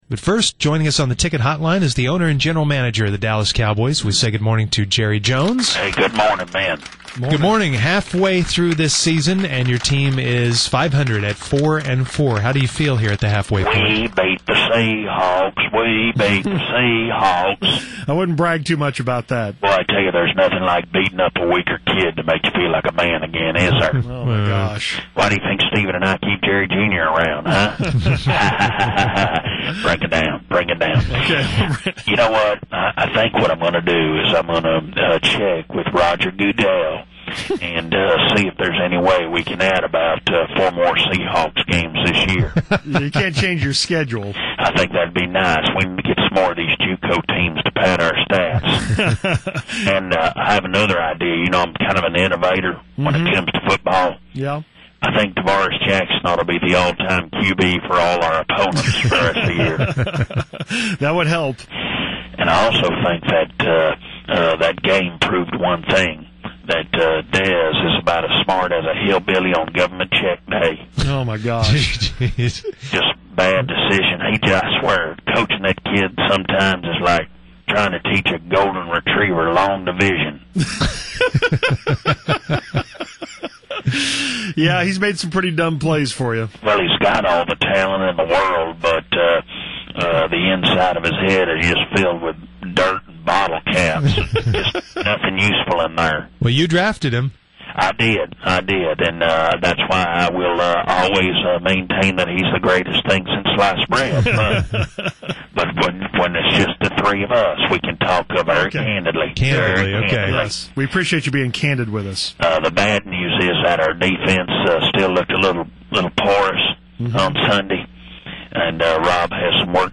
Fake Jerruh calls in and shares his joy over the Seahawks win. His joy is very short-lived though, and the interview takes a strange twist at the end … pray for Jerry, will ya pal?